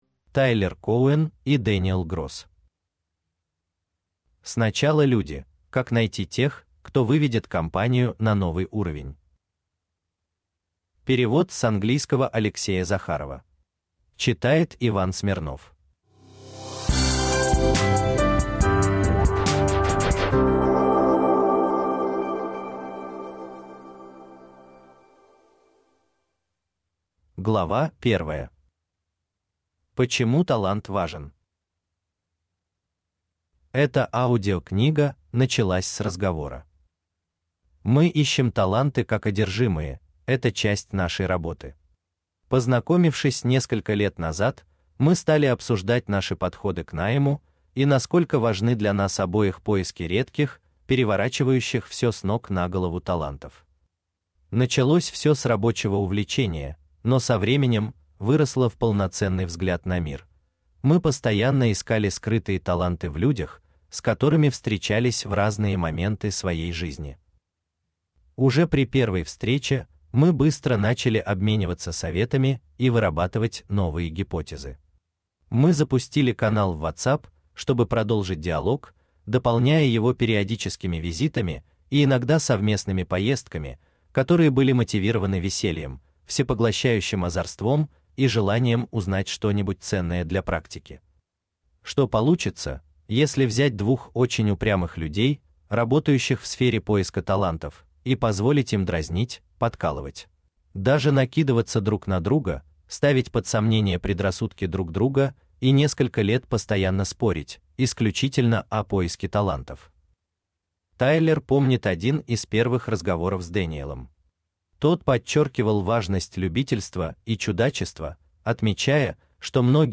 Аудиокнига Сначала люди.